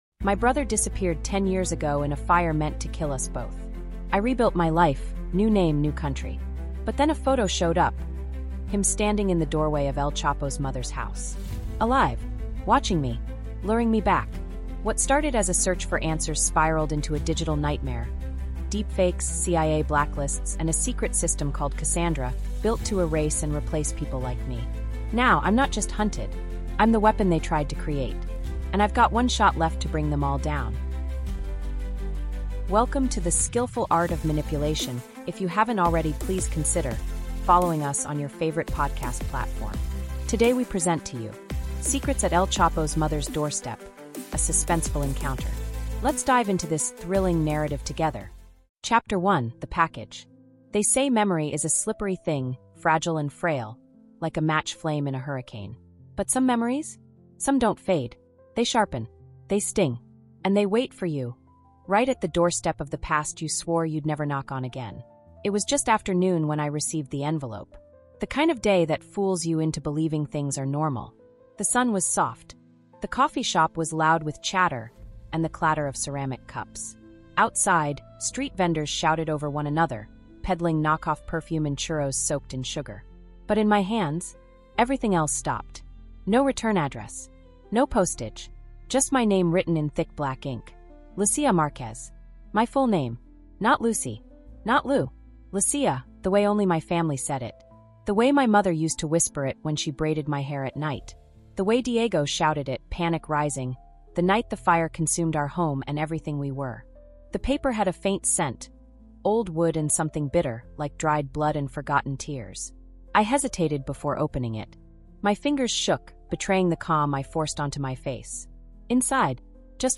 Secrets at El Chapo’s Mother’s Doorstep: A Suspenseful Encounter | Audiobook